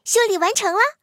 T43修理完成提醒语音.OGG